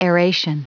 Prononciation du mot : aeration
aeration.wav